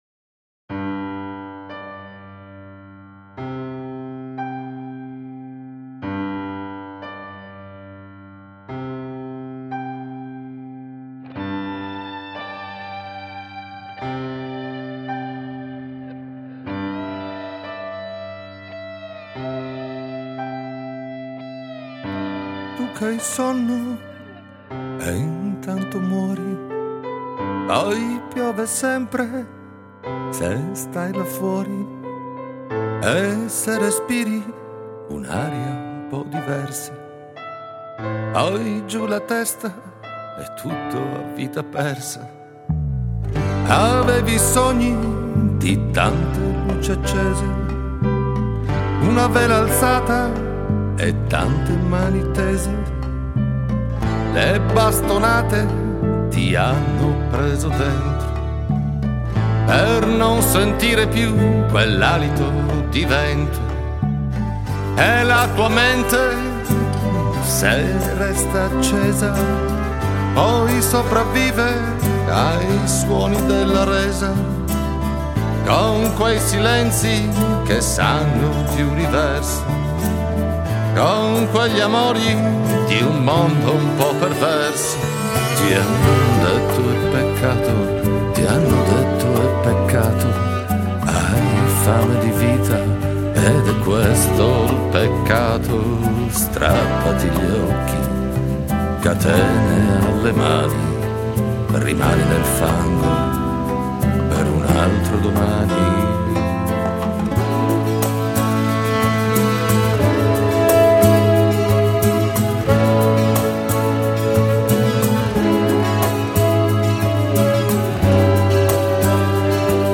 una band acustica
Lead Vocal
Guitars
Percussions
Bass